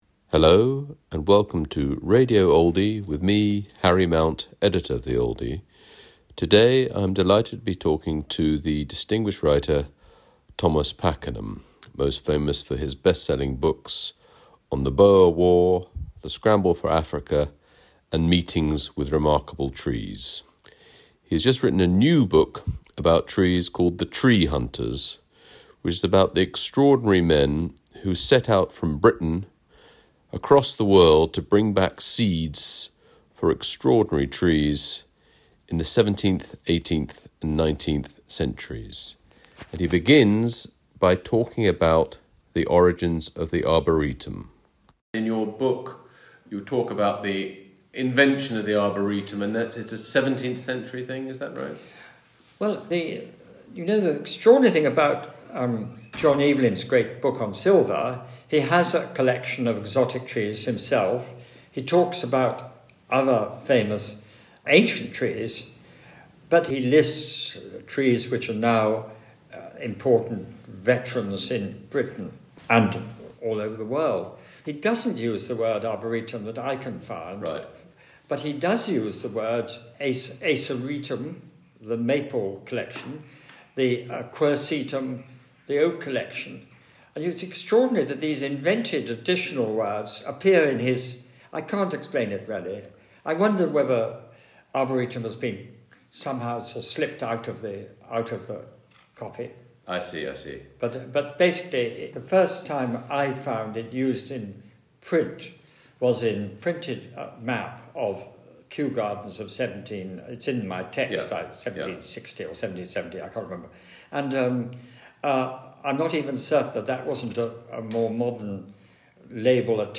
Thomas Pakenham, 91, tells Harry Mount about his new book, The Tree Hunters. From the 17th century to the 19th century, British daredevils headed across the world to bring back seeds for arboretums back home.